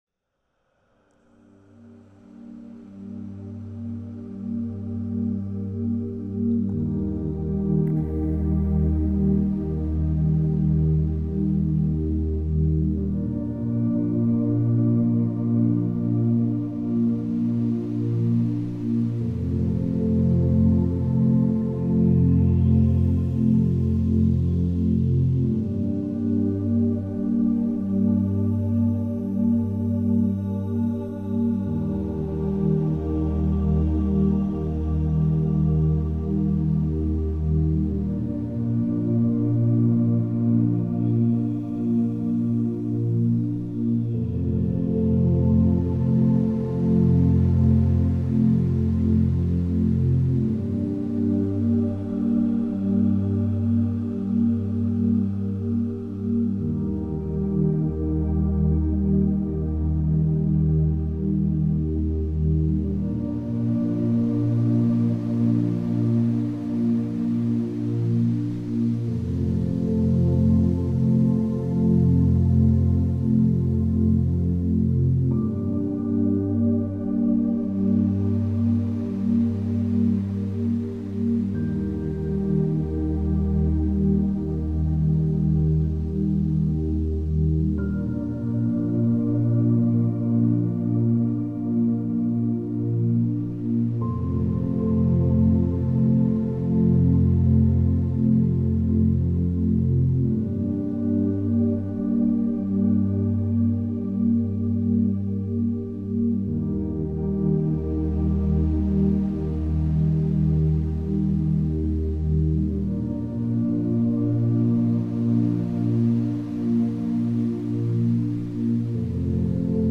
Ocean Stillness | Underwater Calm for Mind and Body
Whether you're winding down after a long day, meditating in the morning, or simply creating a peaceful atmosphere in your home or workspace, Relaxing Sounds delivers a soothing audio experience designed to calm your mind and reset your energy. Each episode of Relaxing Sounds is filled with soft ambient audio—gentle ocean waves, forest breeze, crackling fireplaces, Tibetan bowls, wind chimes, flowing water, and other calming environments that bring you closer to nature.